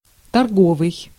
Ääntäminen
Tuntematon aksentti: IPA: /kɔ.mɛʁ.sjal/